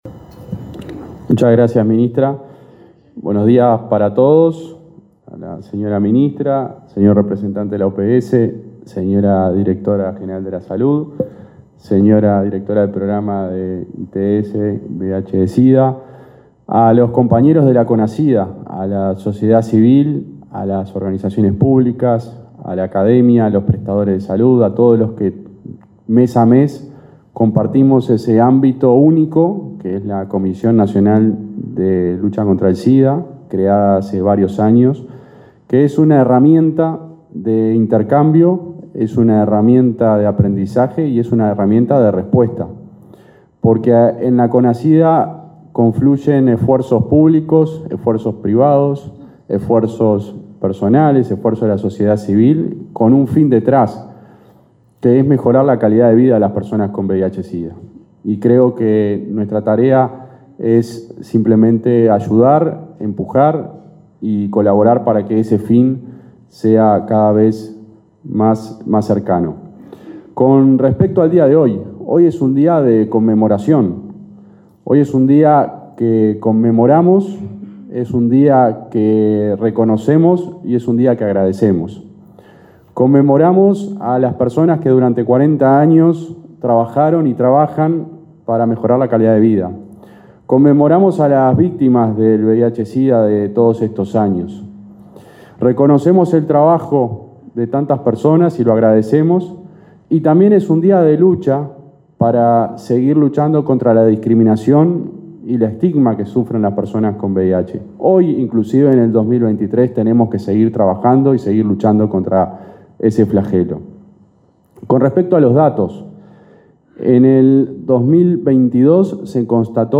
Palabras del subsecretario de Salud Pública, José Luis Satdjian
Palabras del subsecretario de Salud Pública, José Luis Satdjian 27/07/2023 Compartir Facebook X Copiar enlace WhatsApp LinkedIn Este jueves 27, en el Ministerio de Salud Pública, el subsecretario de la cartera, José Luis Satdjian, participó en el acto por el Día Nacional de Lucha contra el VIH/Sida.